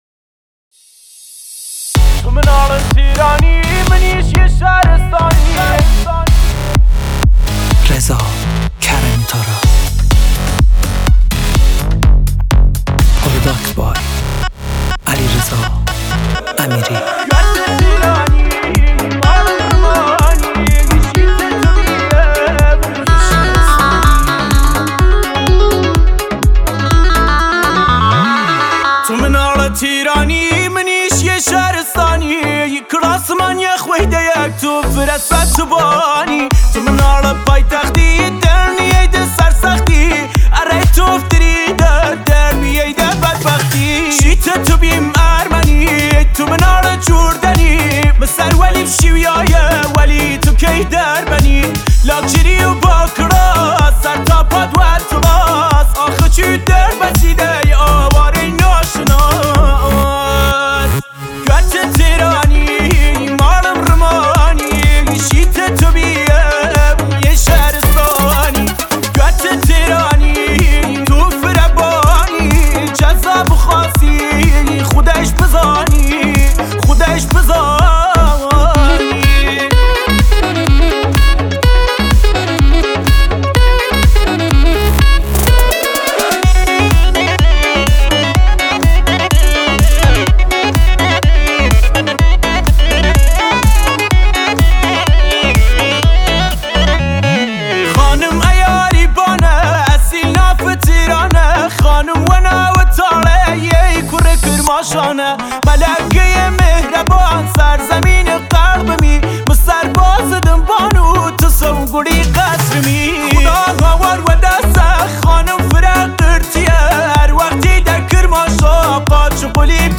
6 اسفند 1400آهنگ جدید ، ریمیکس